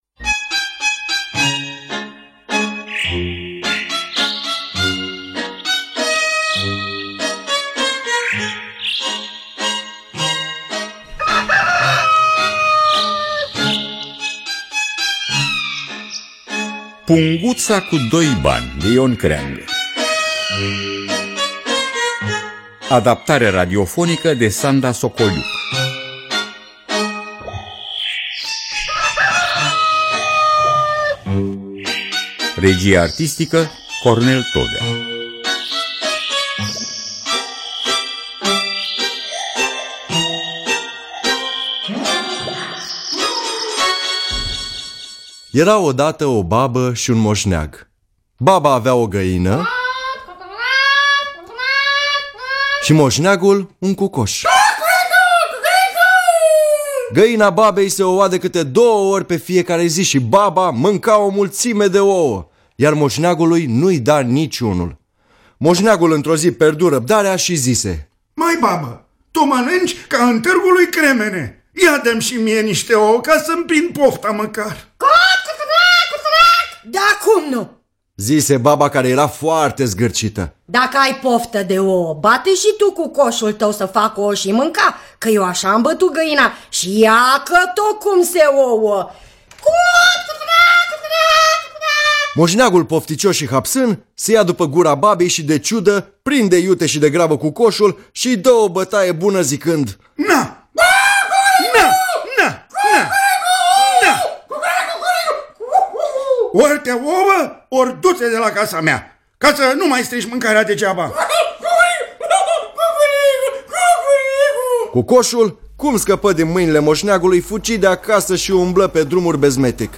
Punguța cu doi bani de Ion Creangă – Teatru Radiofonic Online